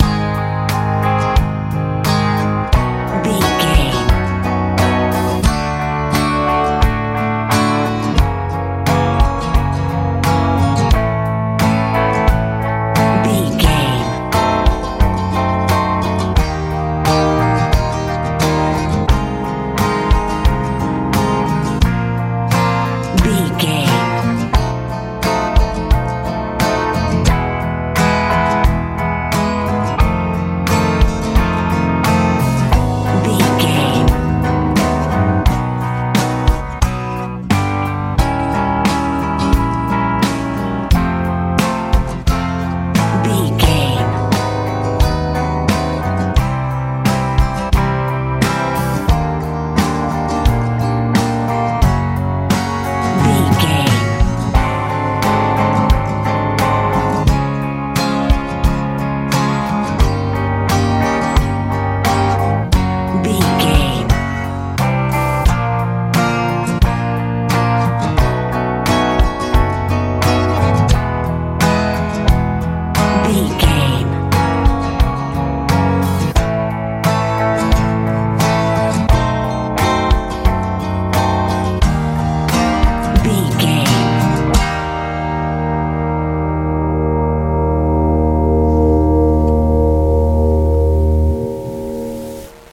modern pop feel
Ionian/Major
G♯
calm
acoustic guitar
electric guitar
bass guitar
drums
soft
smooth